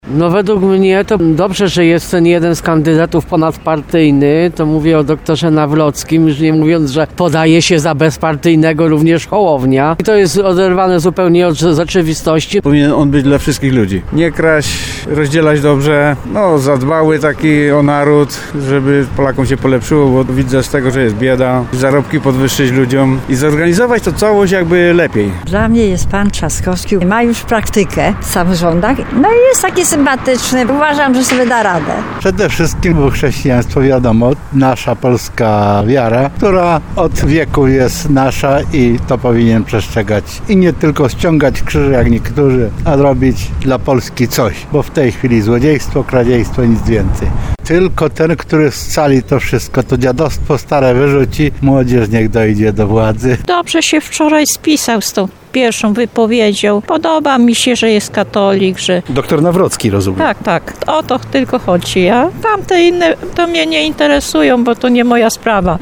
Mieszkańcy Tarnowa oczekują, że następca prezydenta Andrzeja Dudy będzie dbał o Polaków:
25sonda-prezydent.mp3